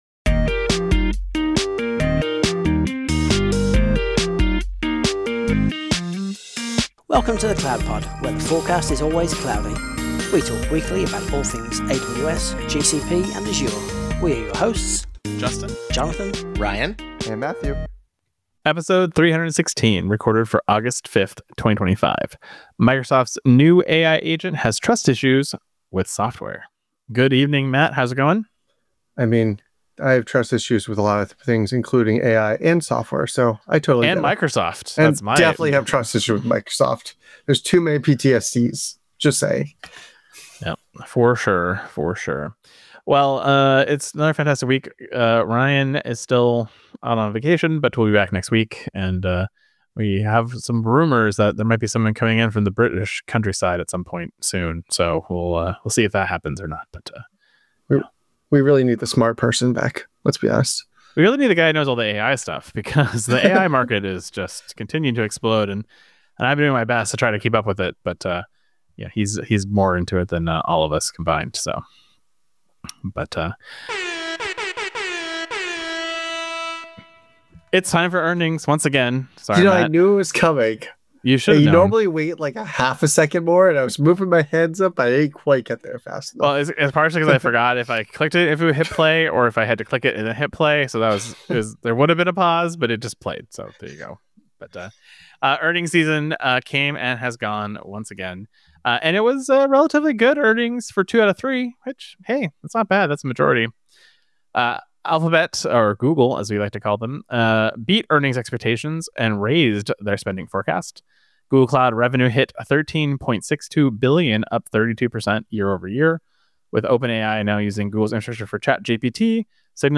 This week we’ve got earnings (with sound effects, obviously) as well as news from DeepSeek, DocumentDB, DigitalOcean, and a bunch of GPU news.